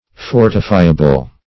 Search Result for " fortifiable" : The Collaborative International Dictionary of English v.0.48: Fortifiable \For"ti*fi`a*ble\, a. [Cf. OF. fortifiable.] Capable of being fortified.